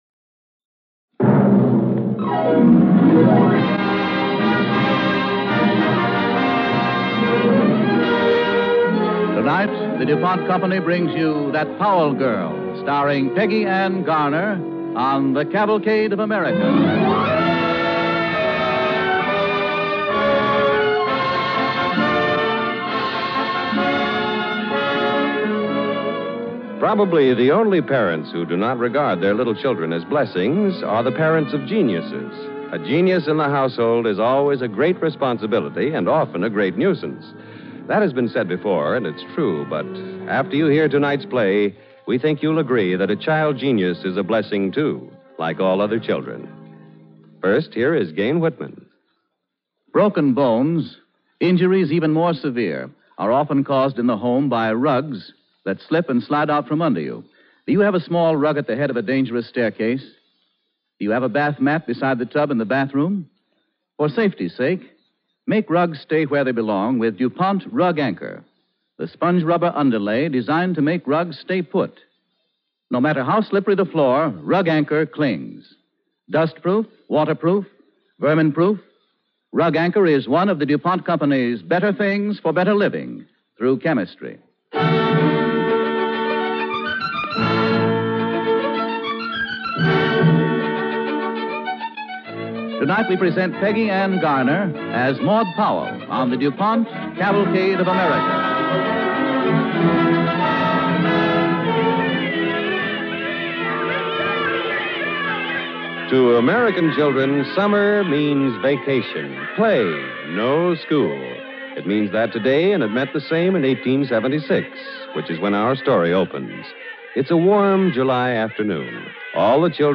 starring Peggy Ann Garner
Cavalcade of America Radio Program